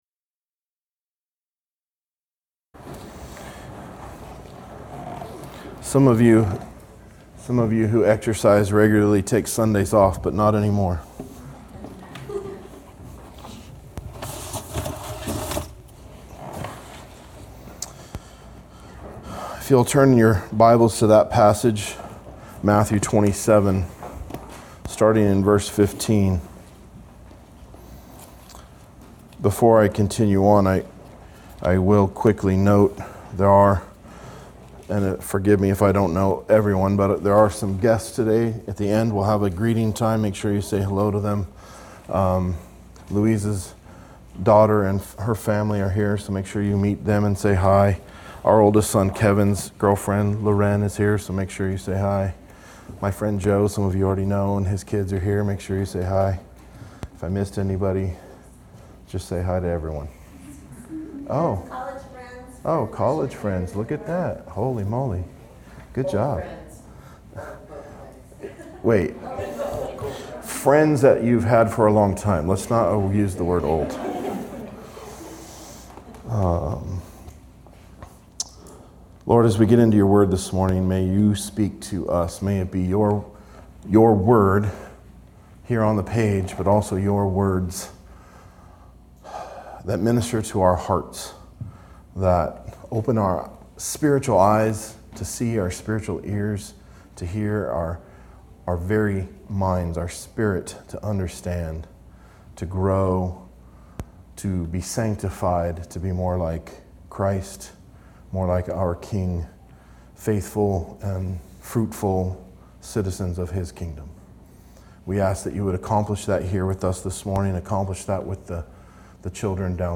Expository teaching of Matthew 27:15-26